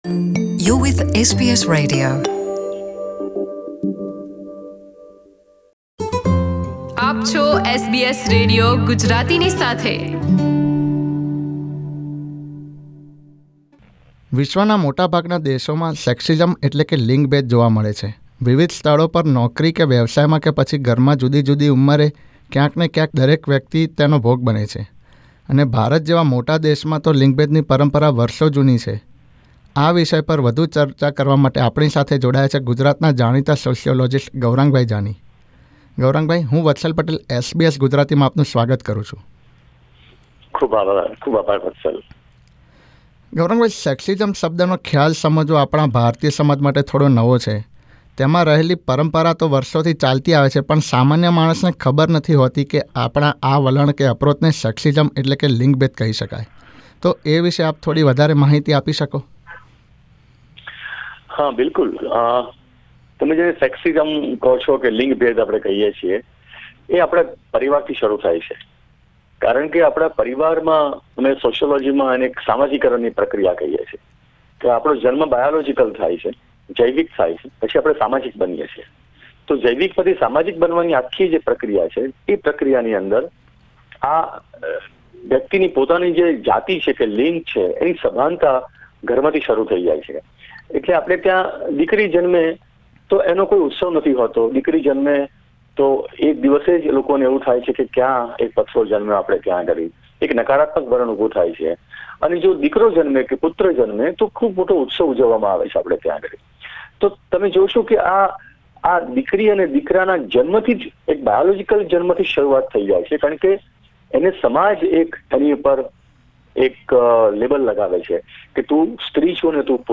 SBS Gujarati સાથેની વાતચીતમાં